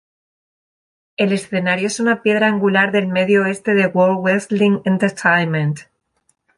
an‧gu‧lar
/anɡuˈlaɾ/